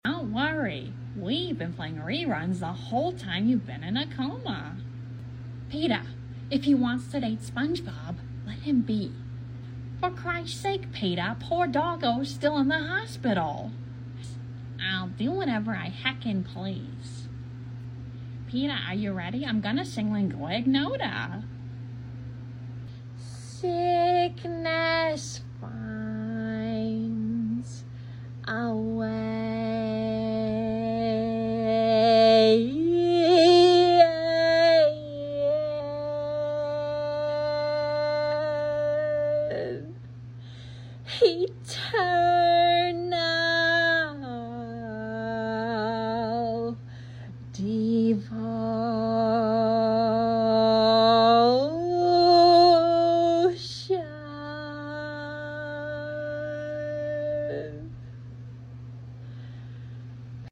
with Lois's voice